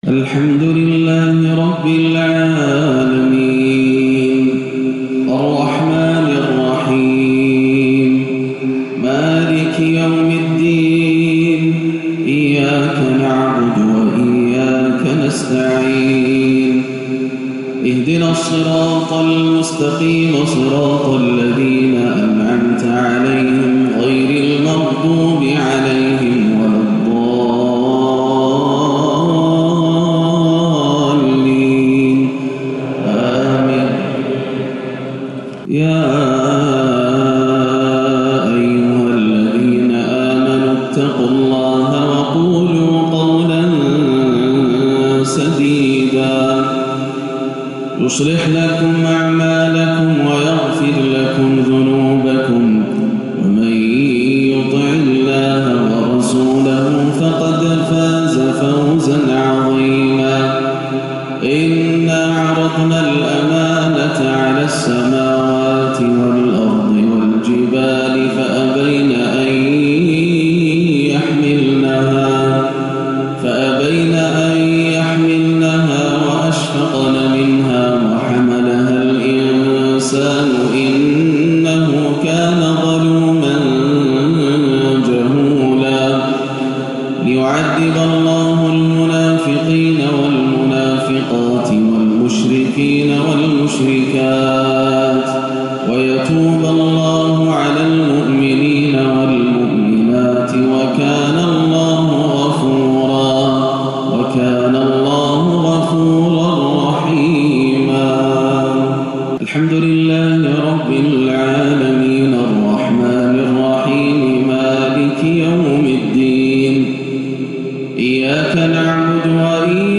مغرب الإثنين 7-4-1439هـ من سورتي الأحزاب 70-73 والنبأ 31-40 > عام 1439 > الفروض - تلاوات ياسر الدوسري